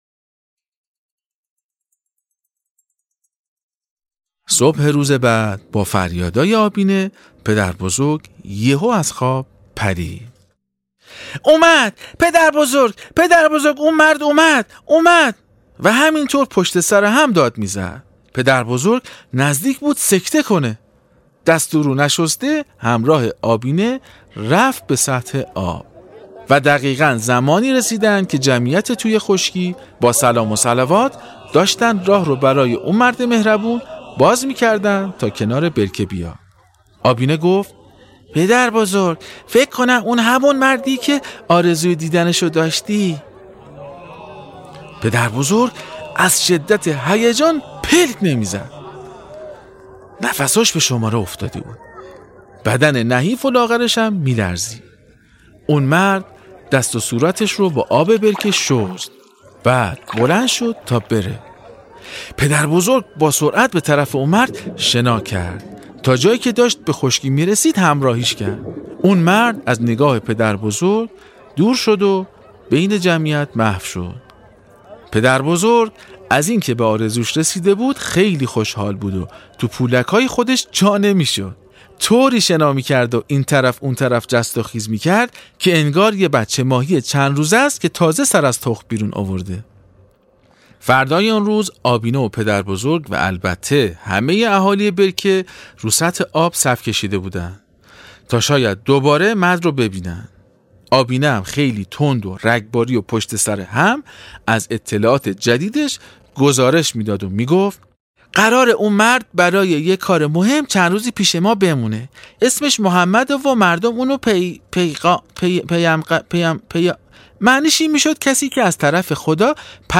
داستان ماهی ای که از یه جریان خیلی مهم برامون صحبت میکنه. باهم قسمت سوم از داستان صوتی آبینه رو بشنویم.